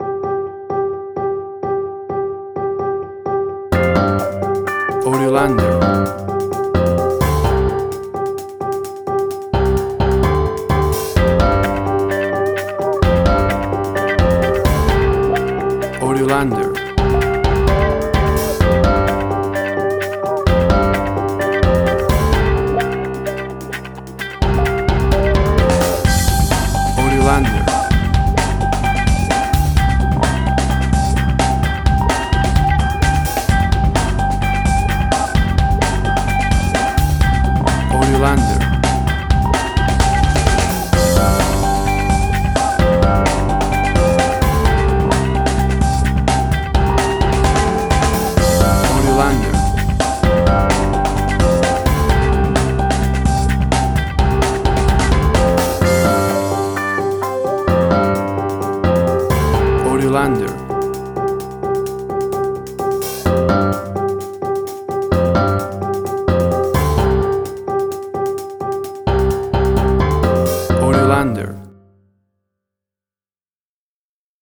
Suspense, Drama, Quirky, Emotional.
WAV Sample Rate: 16-Bit stereo, 44.1 kHz
Tempo (BPM): 129